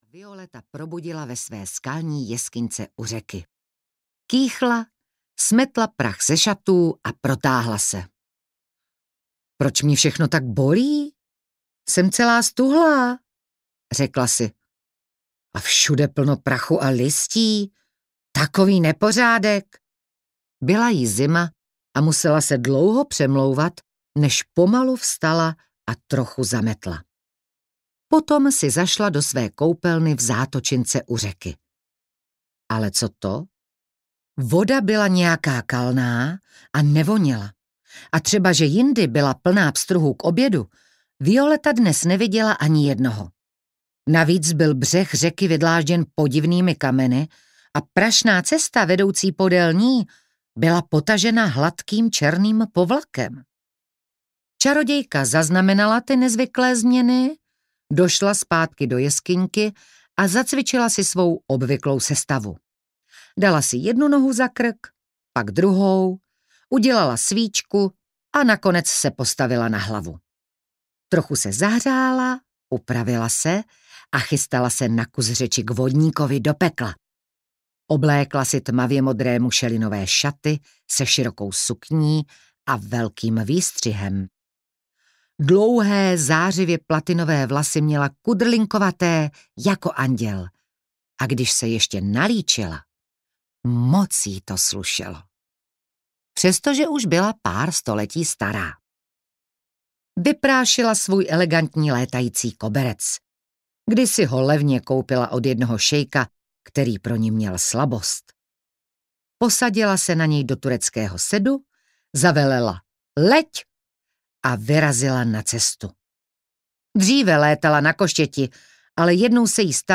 O čarodějce, která zaspala století audiokniha
Ukázka z knihy
Audioknihu čte oblíbená česká herečka Simona Babčáková, která svým výrazným hlasem a hereckým talentem dokáže postavám vdechnout život a příběhu dodat nezapomenutelnou atmosféru.